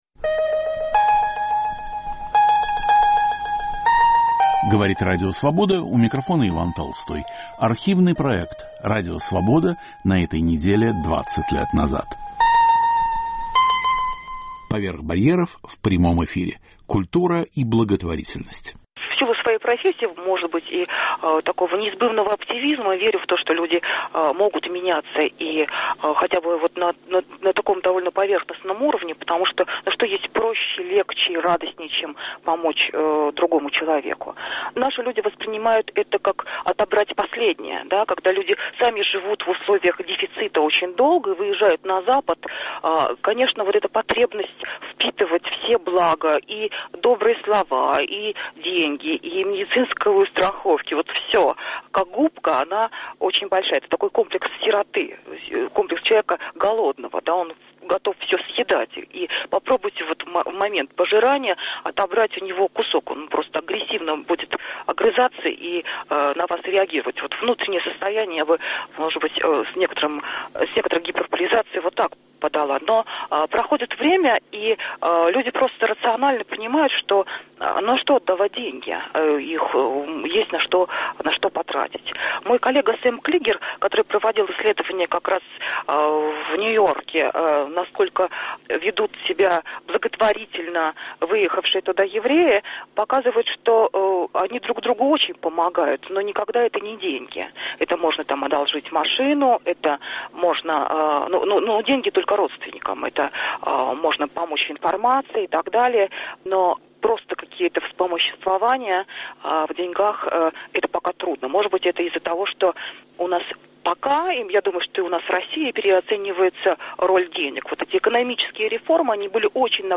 "Поверх барьеров" в прямом эфире. Культура и благотворительность